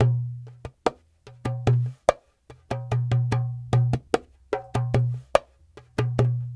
47 Dumbek 11.wav